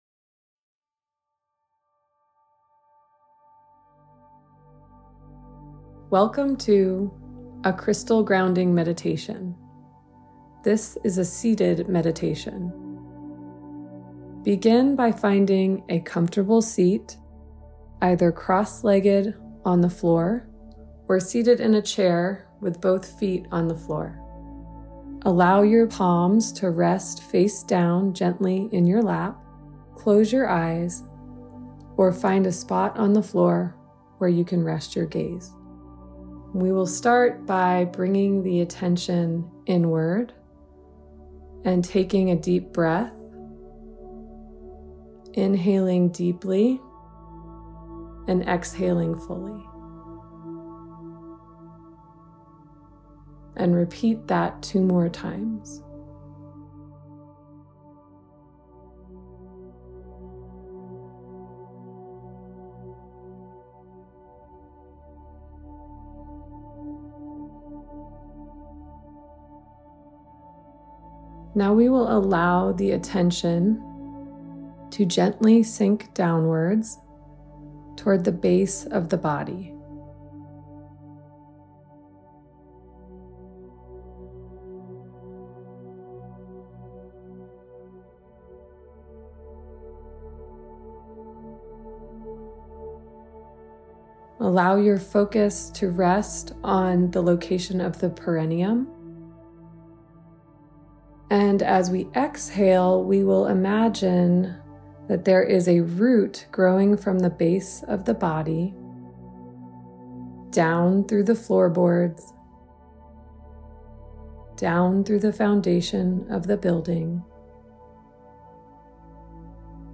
GUIDED MEDITATIONS AND OTHER OFFERINGS